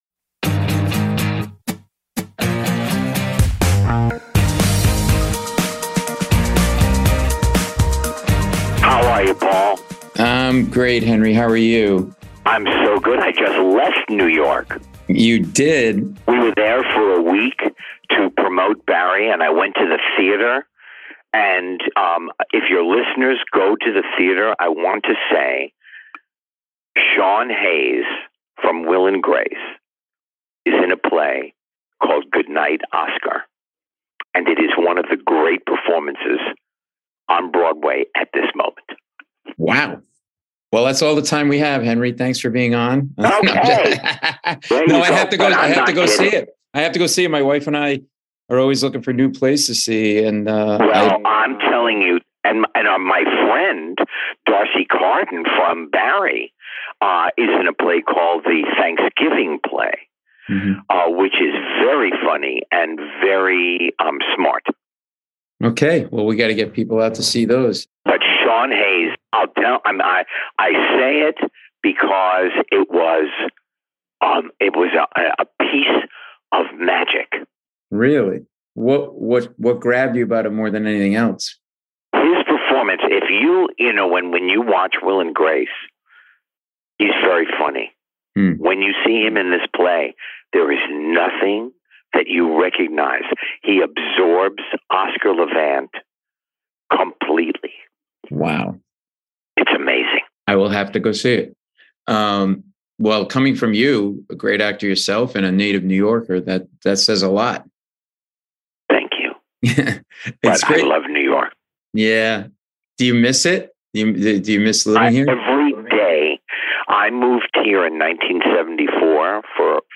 Great to have Henry back on the show in a fun conversation about this complex, quirky, funny character Gene Cousineau in HBO's "Barry."